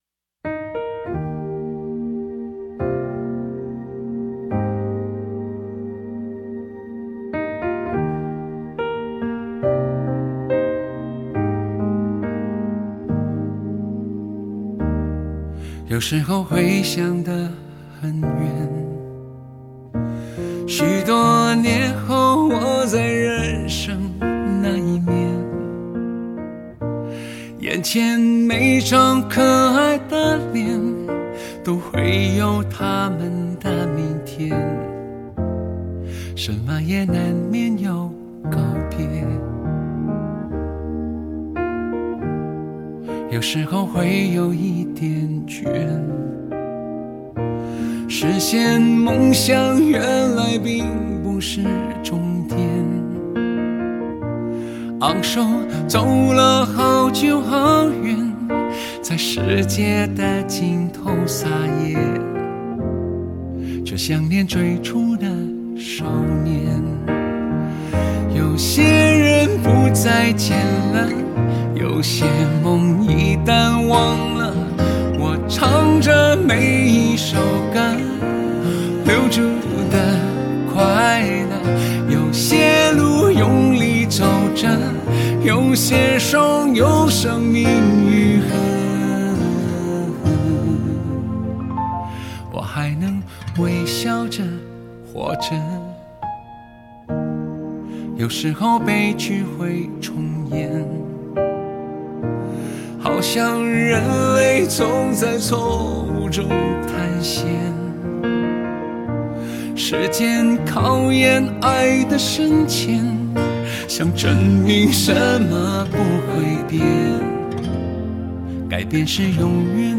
这张全新专辑音乐以民谣摇滚为基底
几把吉他，几个伙伴，加上浑厚真切的好声音，让人遥想起披头四狂放自在的年代。